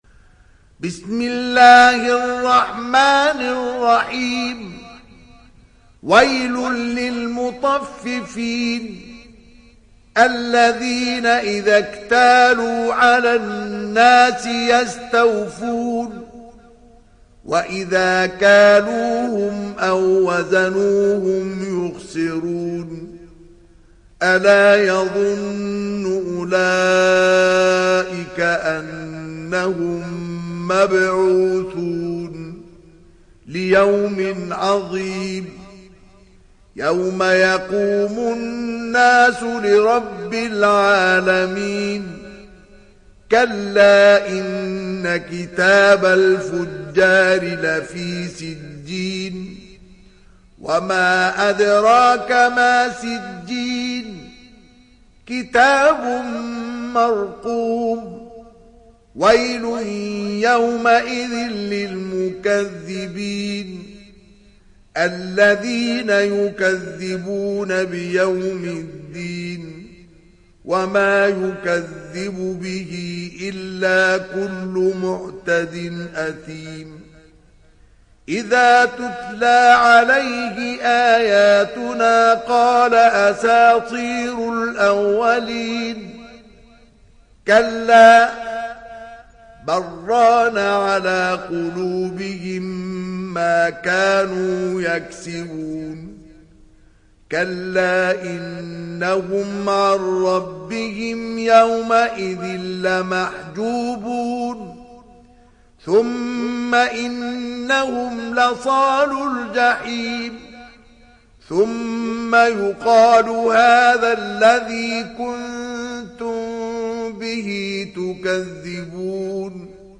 تحميل سورة المطففين mp3 بصوت مصطفى إسماعيل برواية حفص عن عاصم, تحميل استماع القرآن الكريم على الجوال mp3 كاملا بروابط مباشرة وسريعة